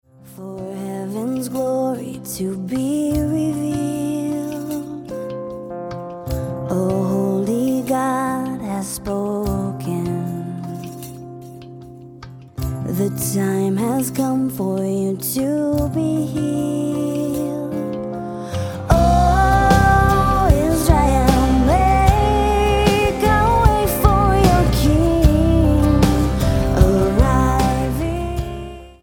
Roots/Acoustic
Style: Pop